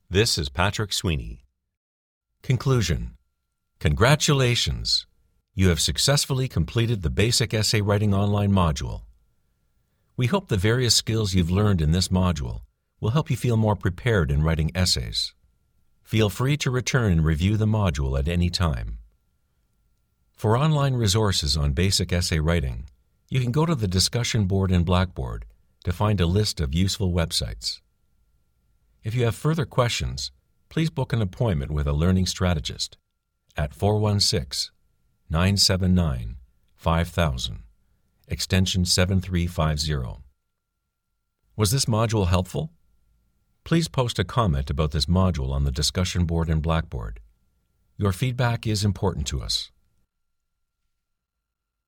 Offering versatility in voice delivery and quick turn around services.
Sprechprobe: eLearning (Muttersprache):
Warm, conversational, friendly voice, but versatile enough to deliver corporate, authoritative, announcer like reads.